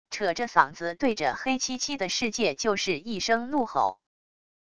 扯着嗓子对着黑漆漆的世界就是一声怒吼wav音频生成系统WAV Audio Player